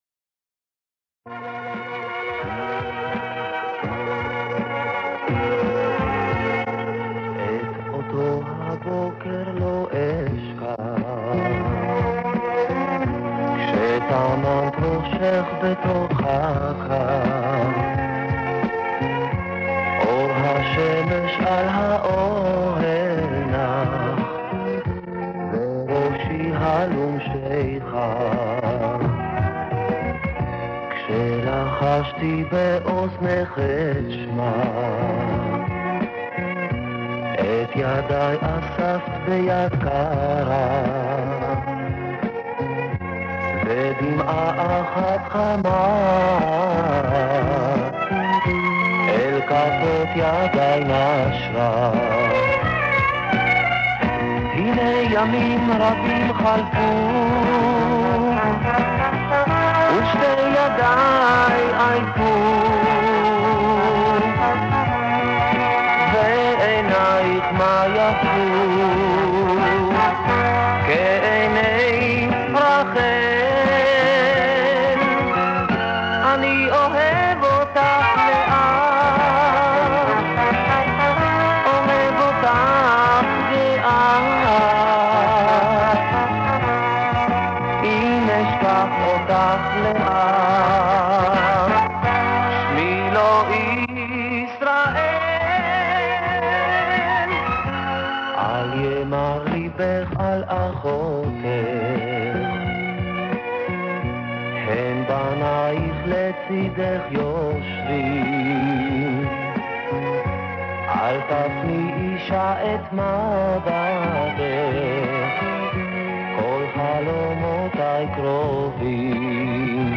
По этой тематике у меня очень мало...(запись с радиоприемника 1975г)